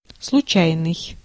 Ääntäminen
Synonyymit fortuit fait par hasard fait sans le faire exprès imprévu casuel Ääntäminen France: IPA: /ak.si.dɑ̃.tɛl/ Haettu sana löytyi näillä lähdekielillä: ranska Käännös Ääninäyte 1. случайный (slutšainyi) Suku: m .